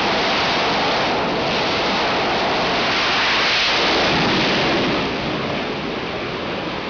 f18launch.wav